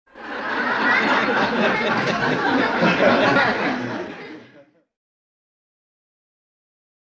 Категория: Аплодисменты и смех
Звук смеха толпы
Так громко смеются